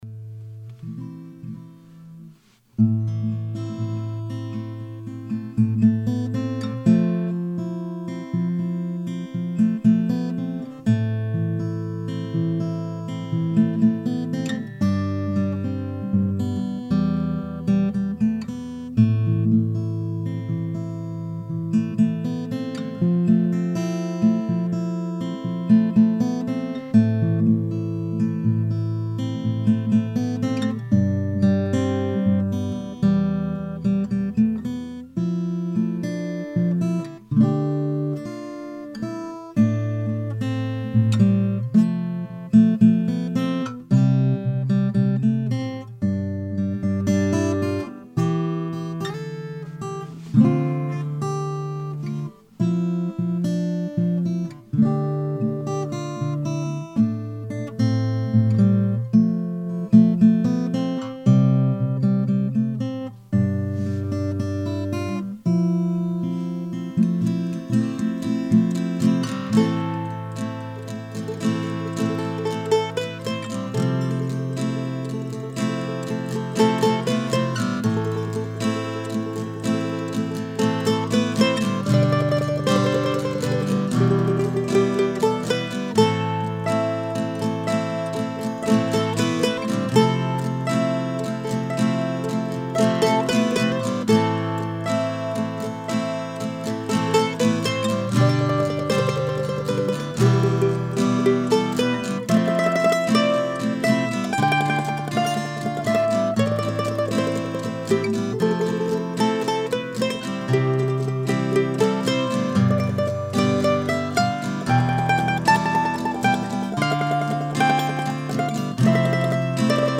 The recording here is another attempt to adapt to the new Tascam DP-008 recorder. This time I used an AKG C1000-S mic instead of the built Tascam mics.